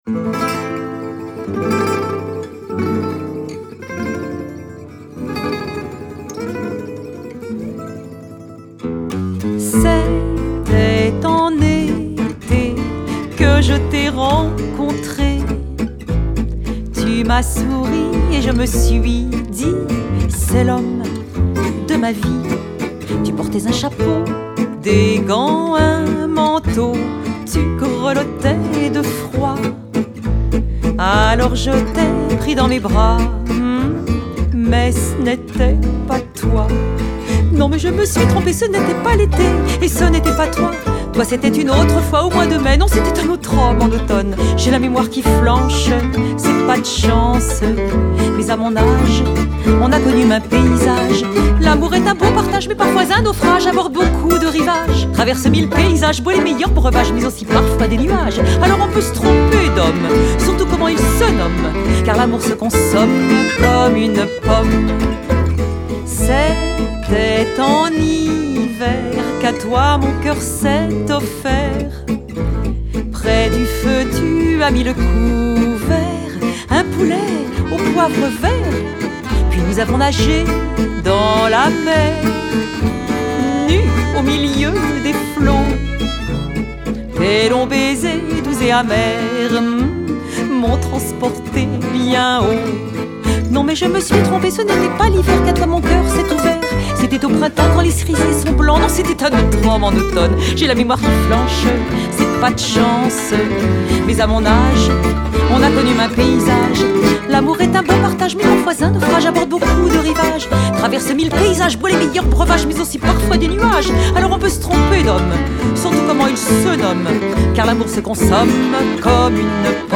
accordéon, voix
guitare
contrebasse